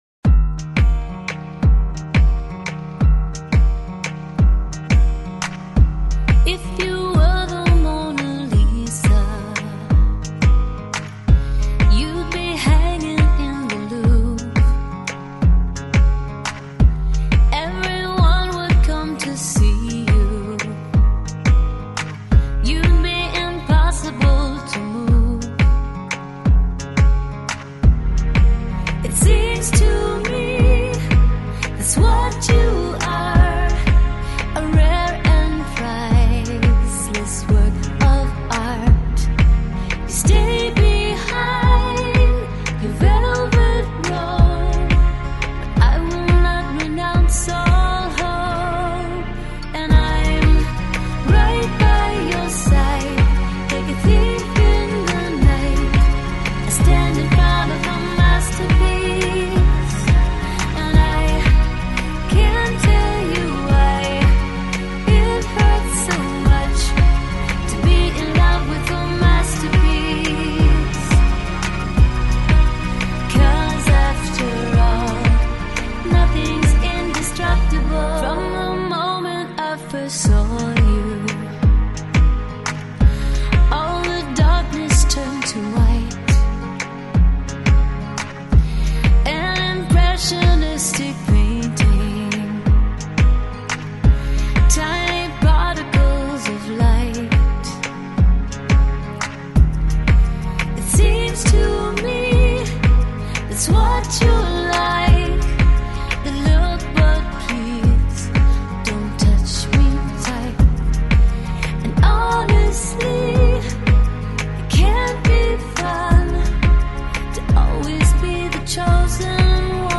banda sonora